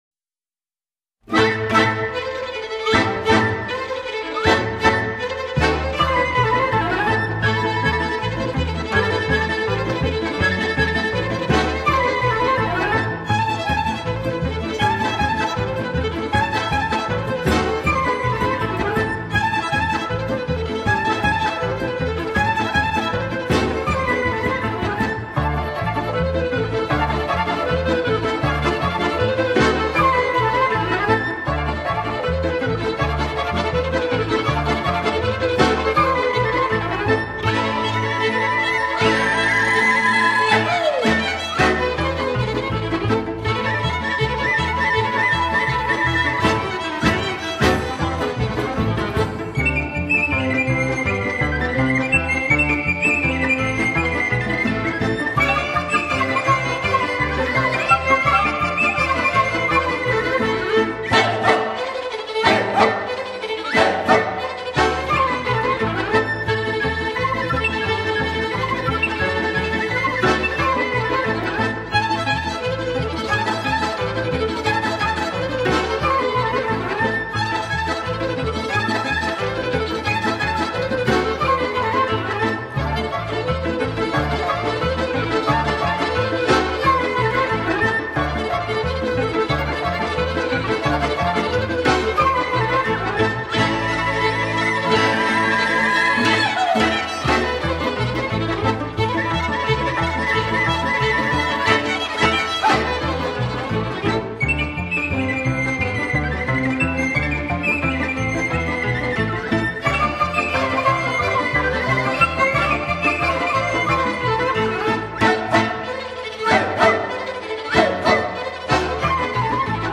» - Ensemble ukrainien de musique traditionnelle